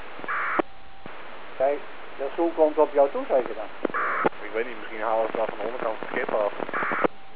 ATIS A VHF/UHF radio signal used on the River Rhine maritime radio.